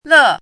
[ lè ]
le4.mp3